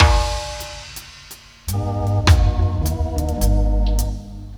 DUBLOOP 08-L.wav